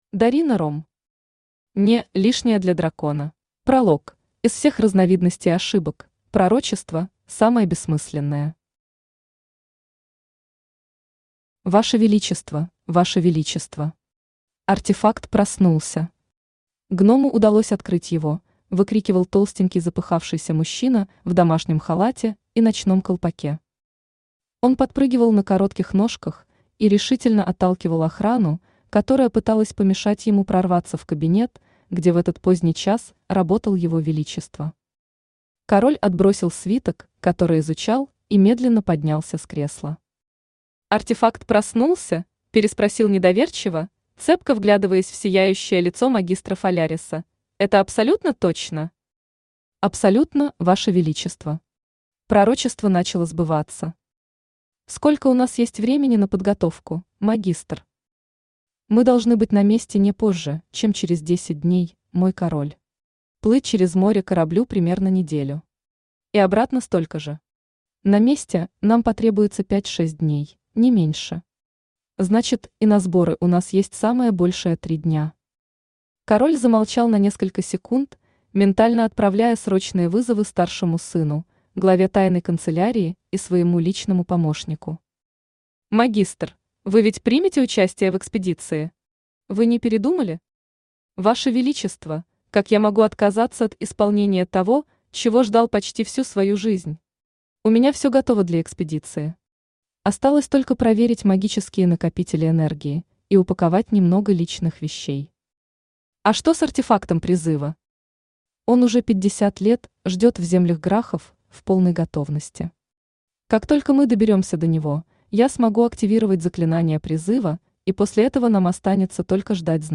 Aудиокнига (Не) Лишняя для дракона Автор Дарина Ромм Читает аудиокнигу Авточтец ЛитРес.